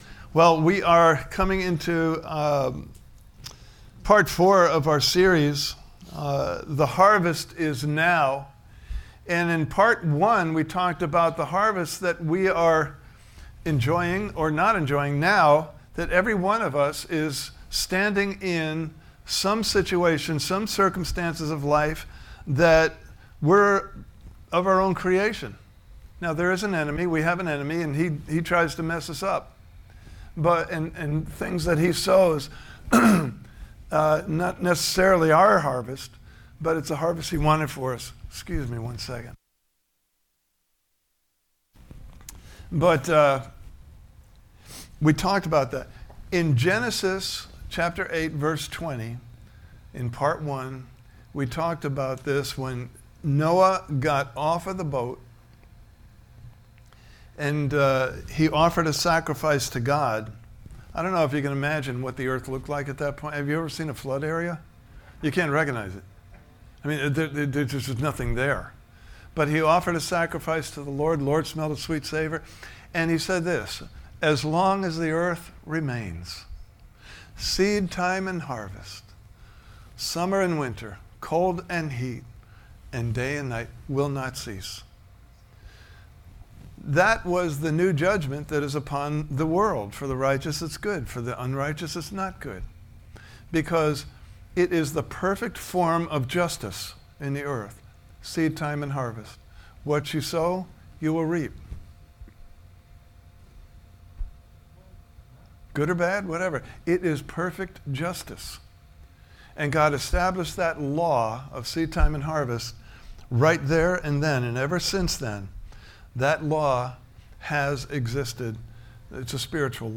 Service Type: Sunday Morning Service « Part 3: Jesus Wants His Harvest!